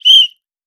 Whistle Blow Intense Stop.wav